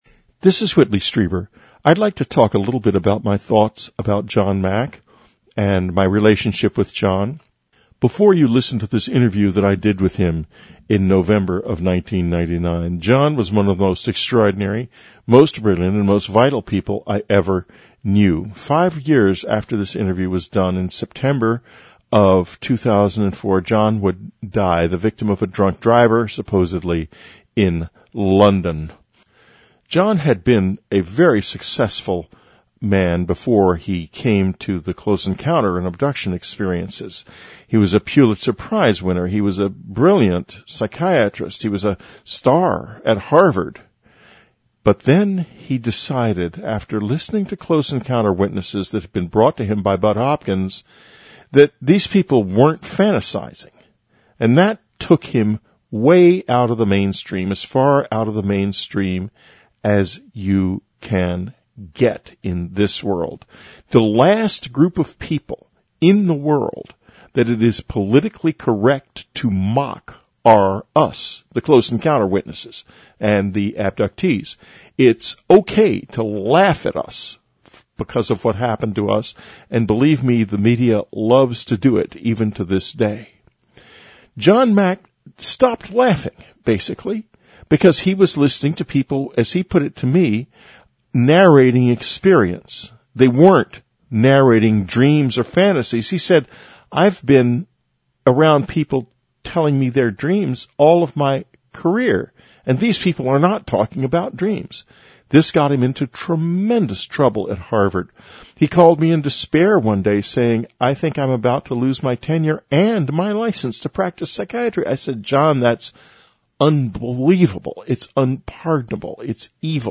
Whitley Strieber Interviews Dr. John Mack
On November 14, 1999, Whitley Strieber interviewed Dr. John Mack for Dreamland. Prior to this presentation of that program, Whitley has added a discussion of his friendship with Dr. Mack and his support of him during the terrible time when he was being pilloried in the press and Harvard was threatening his tenure, and his professional credentials were being challenged.
This interview represents the only time where the world’s best known close encounter witness interviewed the community’s greatest and most articulate advocate.